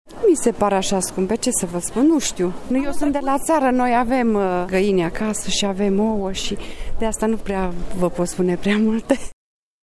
Oamenii spun că s-au obișnuit deja cu scumpirile de Paște:
vox-oua-2.mp3